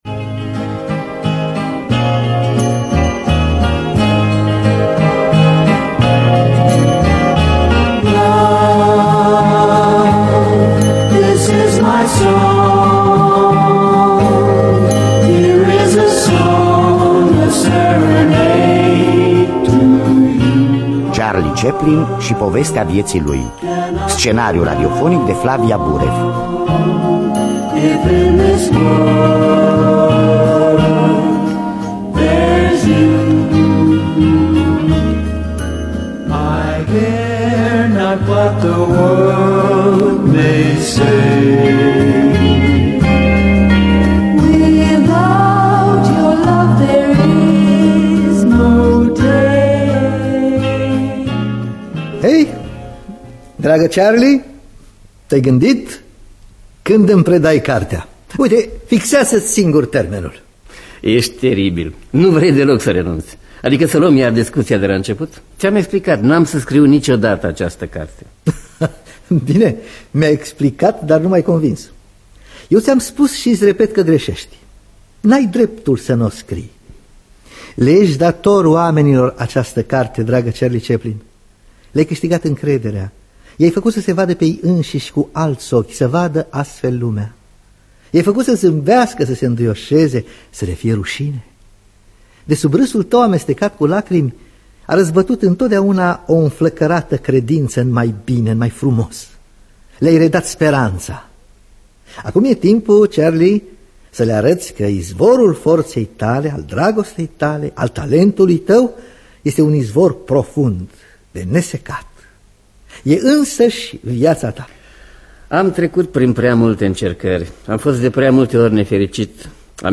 Biografii, Memorii: Charlie Chaplin Si Povestea Vietii Lui (1981) – Teatru Radiofonic Online